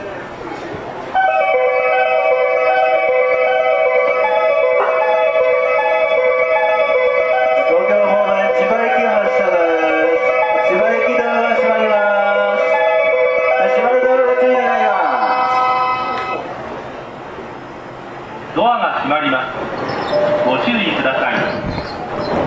発車メロディーの音量が小さい上に駅員さんが喋りまくるので収録は困難です。